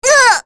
Kirze-Vox_Damage_kr_05.wav